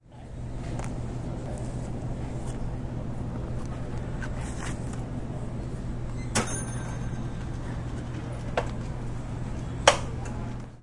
09 树叶上的脚步声；更快的步伐；开放的空间；接近；自然
描述：更快的节奏;开放空间;近;性质;鸟类;脚步声;离开田间记录森林环境
Tag: 场记录 鸟类 开放 环境 森林近 起搏 空间 叶子 脚步 性质 更快